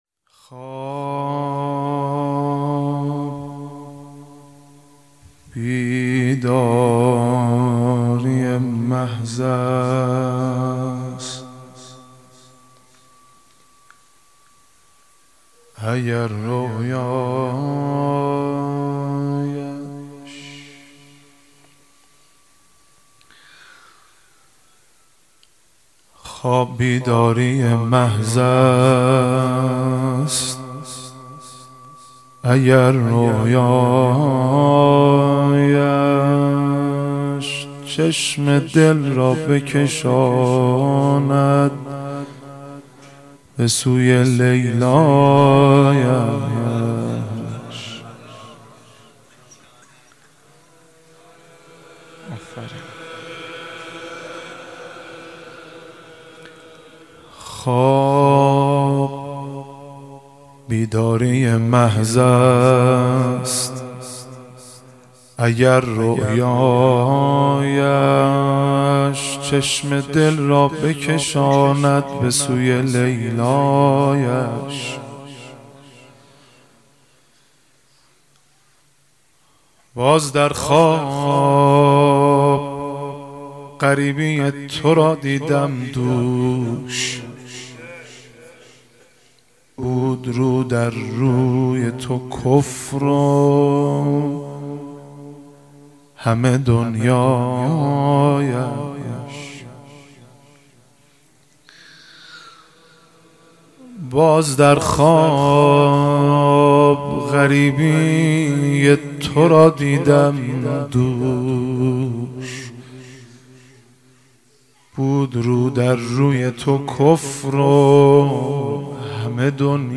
مدح روضه پیش زمینه زمینه زمینه نوحه دودمه شور شور